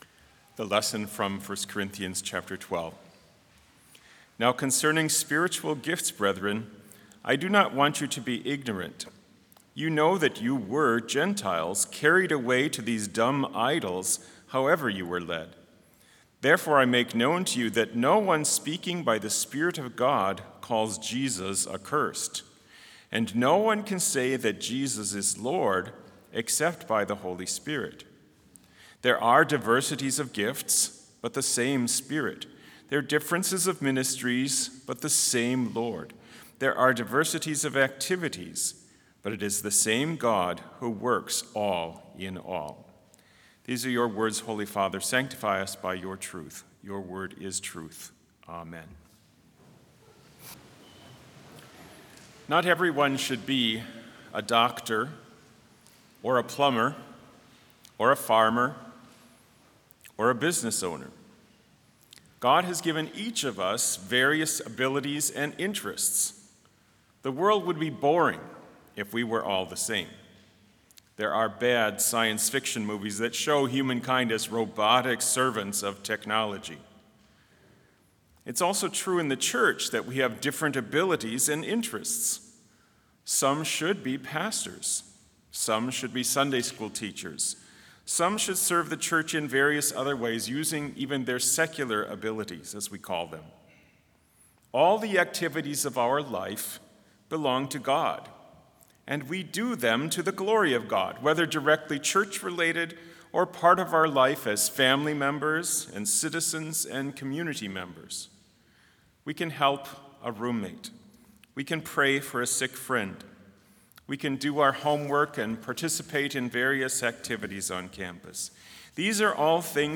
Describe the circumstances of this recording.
This Chapel Service was held in Trinity Chapel at Bethany Lutheran College on Tuesday, August 26, 2025, at 10 a.m. Page and hymn numbers are from the Evangelical Lutheran Hymnary.